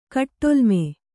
♪ kaṭṭolme